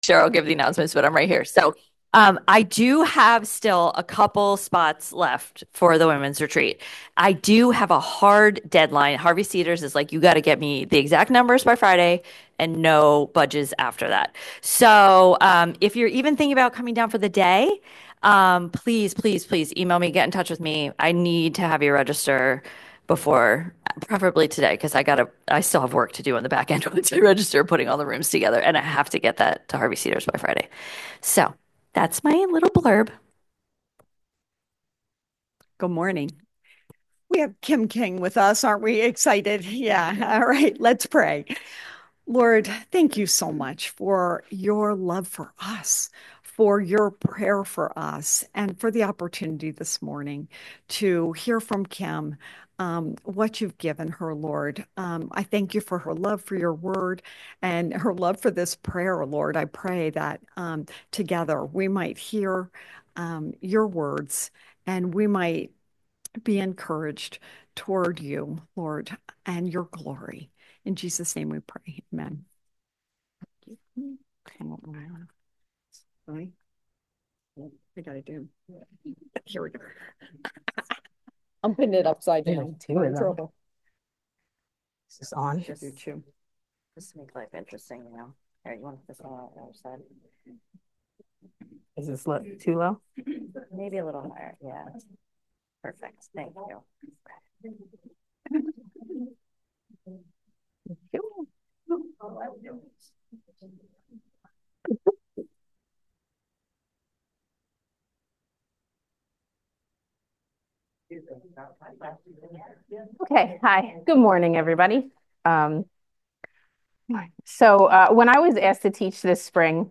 New Life Dresher's Women's Bible Study John 17